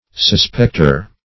Suspecter \Sus*pect"er\, n. One who suspects.